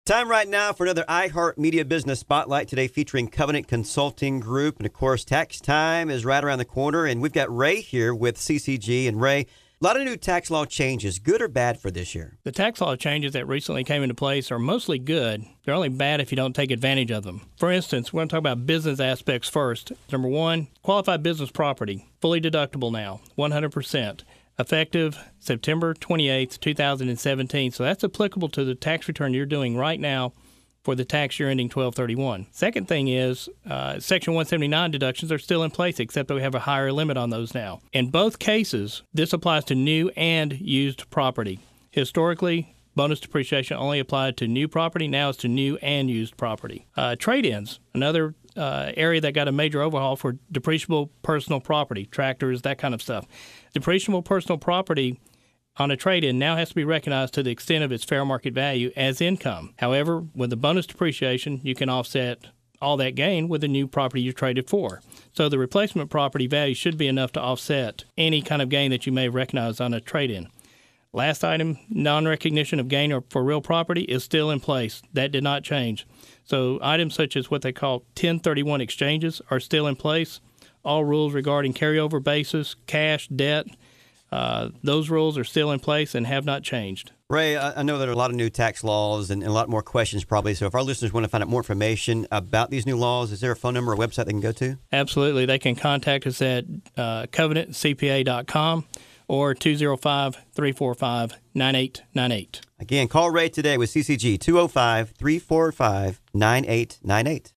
Tax Time with CCG – Monthly Audio Interview